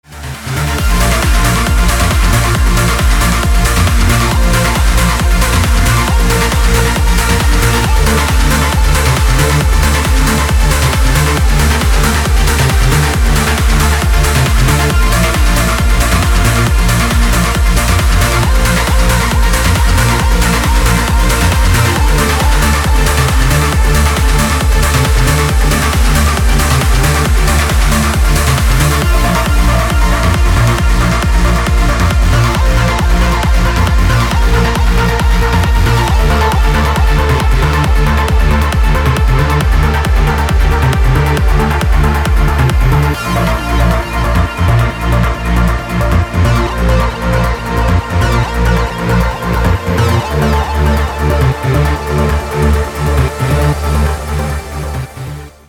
• Качество: 256, Stereo
громкие
dance
электронная музыка
без слов
club
Trance
Uplifting trance